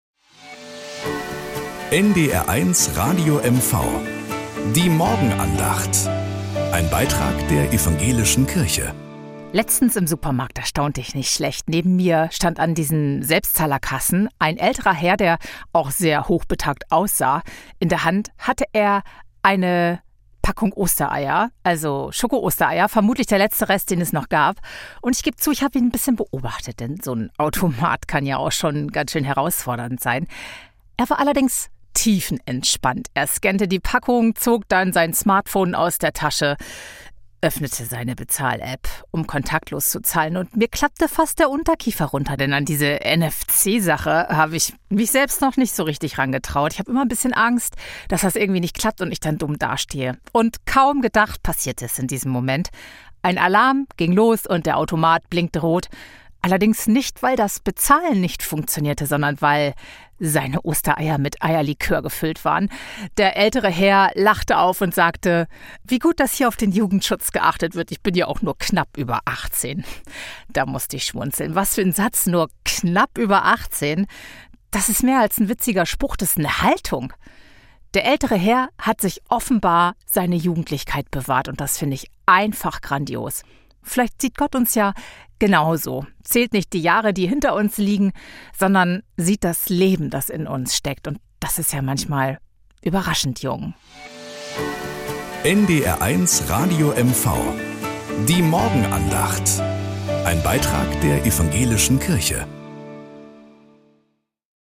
Morgenandacht auf NDR 1 Radio MV
Um 6:20 Uhr gibt es in der Sendung "Der Frühstücksclub" eine